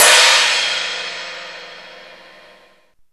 CHINESE01 -R.wav